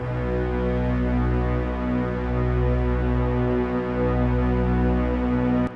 piano-sounds-dev
b3.wav